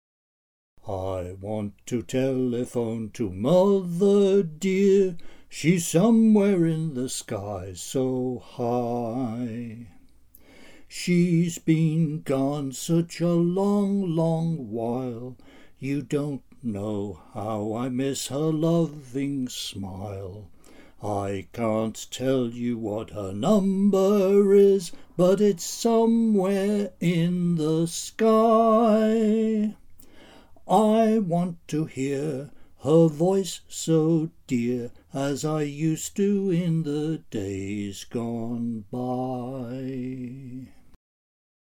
Since I may be the last person on Earth who knows the tune, I have sung as much of it as I can remember
Alas, I have not much of a singing voice.]